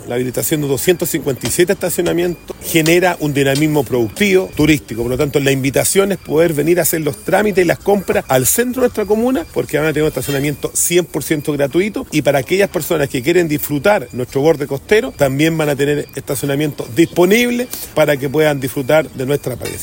El alcalde, Rodrigo Vera, aseguró que la iniciativa beneficiará a residentes y turistas que podrán realizar trámites en el centro de la comuna.